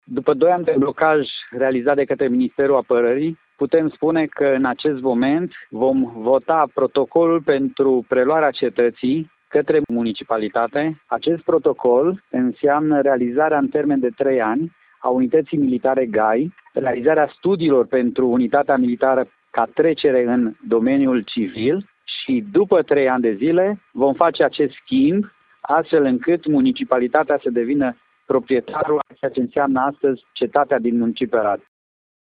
După doi ani de blocaje, municipalitatea arădeană a ajuns la o înţelegere cu Ministerul Apărării privind preluarea cetăţii, iar protocolul va fi semnat în şedinţa de Consiliu Local din 23 decembrie. Primarul Aradului, Gheorghe Falcă, spune că în următorii trei ani vor fi parcurse diverse proceduri după care Cetatea va deveni proprietarea municipalităţii.